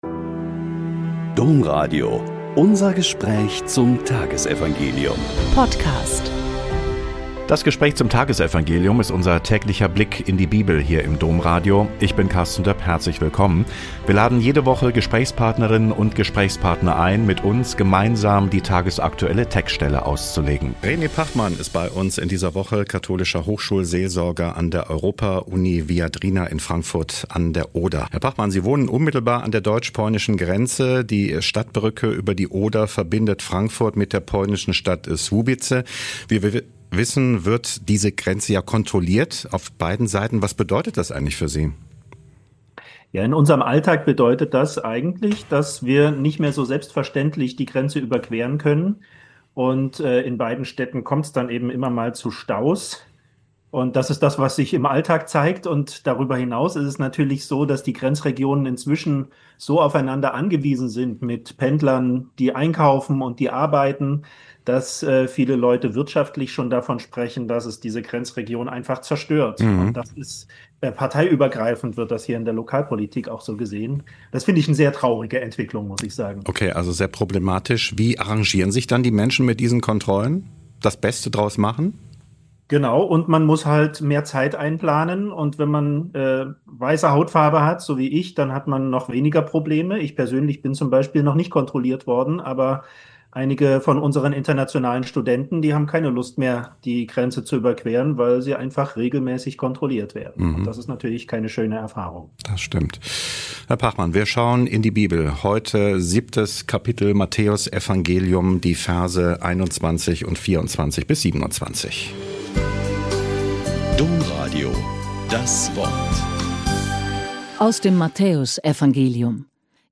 Mt 7,21.24-27 - Gespräch